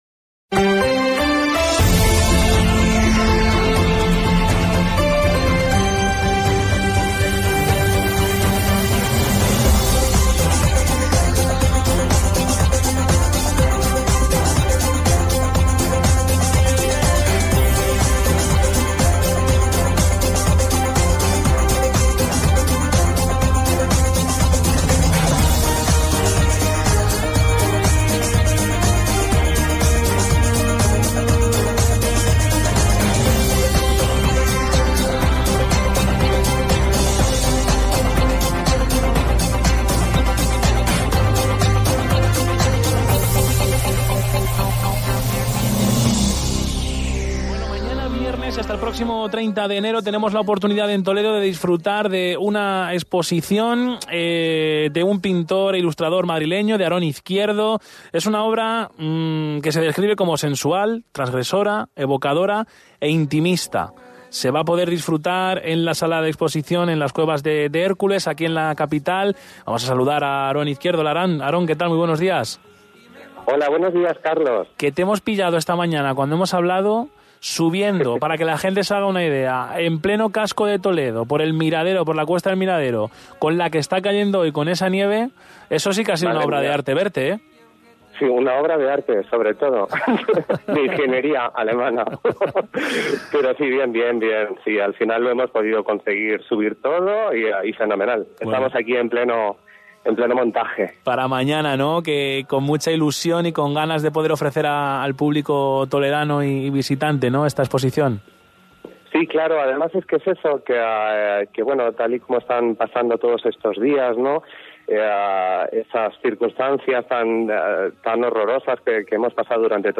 Entrevista-en-cadena-ser.-Hoy-por-hoy..mp3